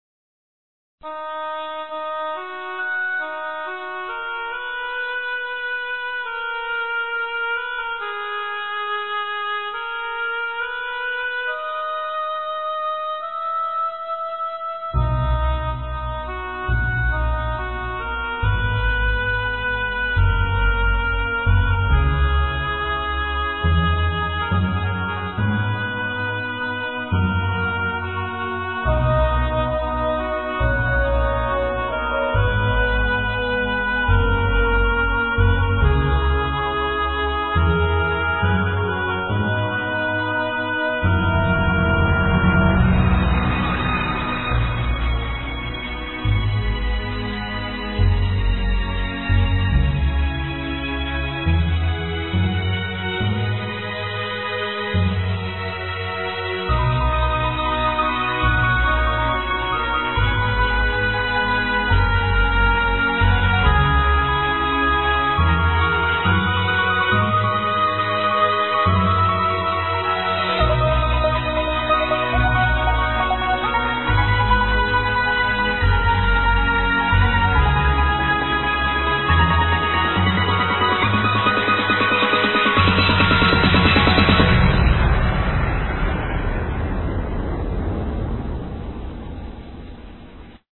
Dreamtrance or GamerMusic???